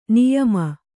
♪ niyama